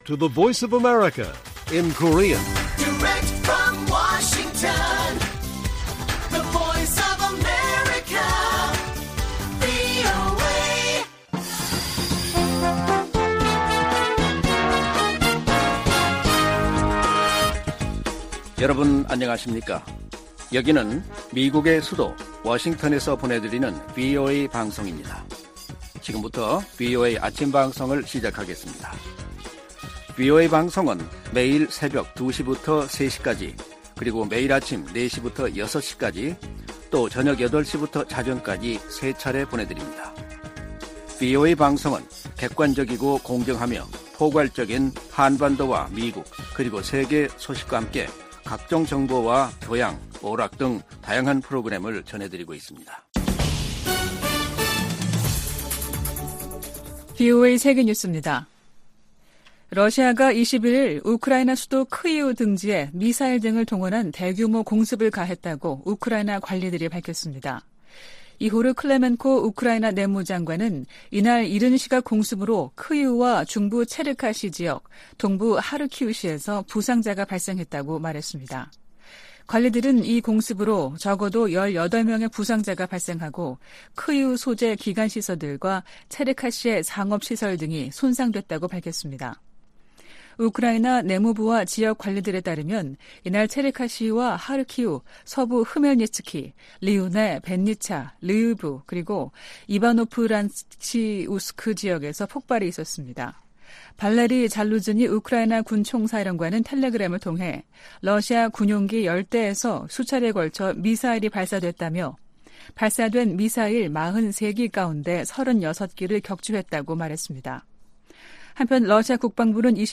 세계 뉴스와 함께 미국의 모든 것을 소개하는 '생방송 여기는 워싱턴입니다', 2023년 9월 22일 아침 방송입니다. '지구촌 오늘'에서는 볼로디미르 젤렌스키 우크라이나 대통령이 유엔 안전보장이사회에서 러시아의 침공은 유엔헌장을 훼손하는 범죄라고 규탄한 소식 전해드리고, '아메리카 나우'에서는 47만여 명에 달하는 베네수엘라 이주민의 합법적인 체류를 허용한다는 이야기 살펴보겠습니다.